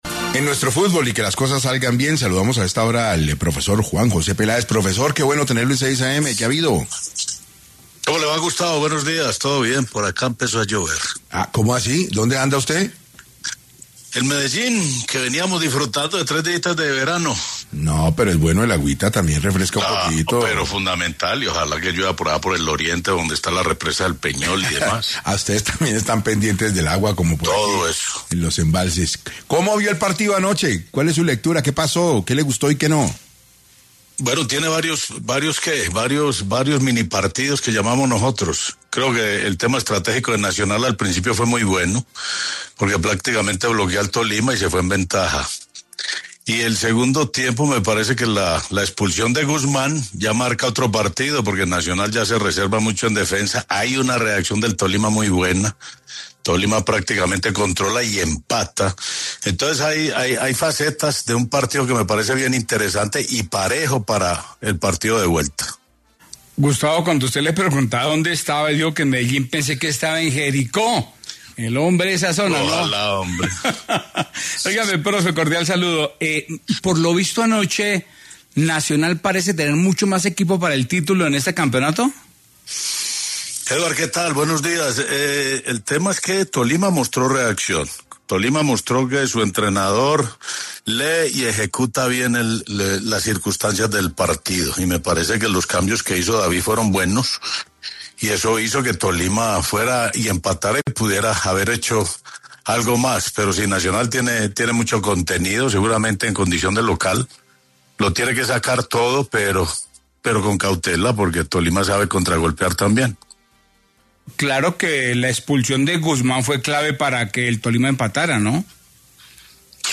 Juan José Peláez, ex entrenador de Atlético Nacional, campeón de la Liga en 1994, la Interamericana en 1995 y subcampeón de la Copa Libertadores en 1995, analizó la final en entrevista al noticiero ‘6AM’ de Caracol Radio.